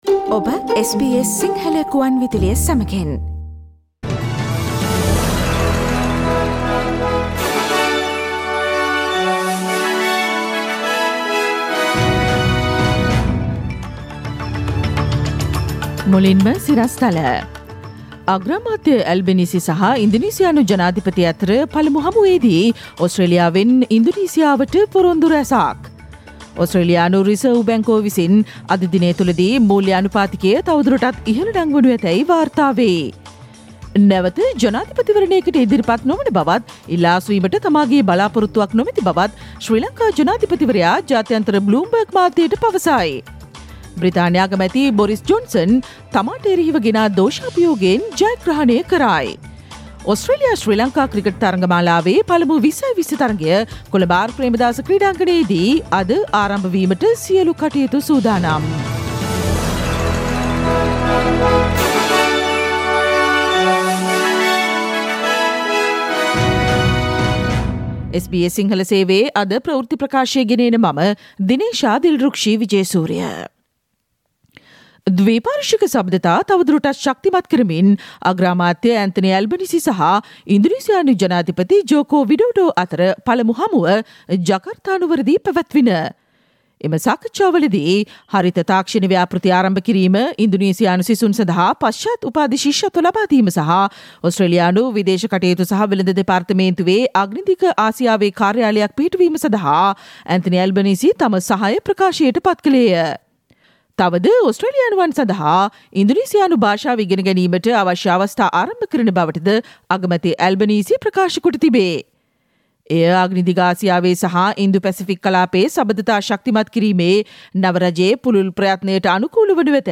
Click on the speaker icon on the image above to listen to the SBS Sinhala Radio news bulletin on Tuesday 07 June 2022.